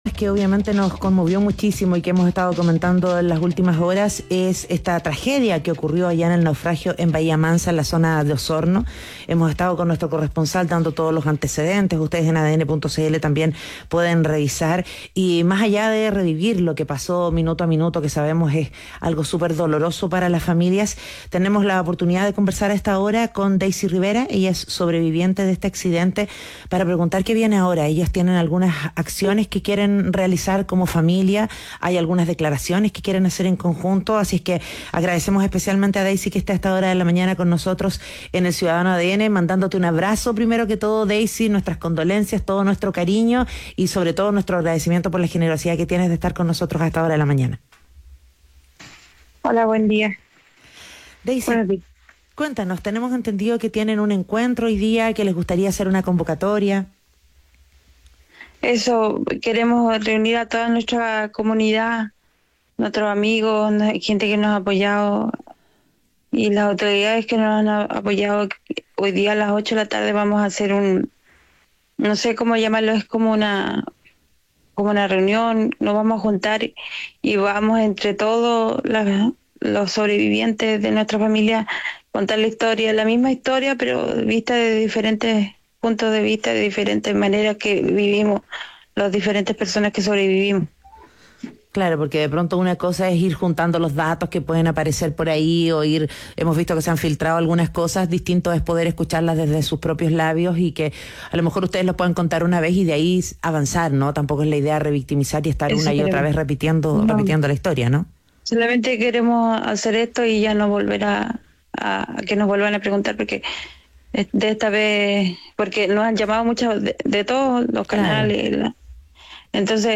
En conversación con Ciudadano ADN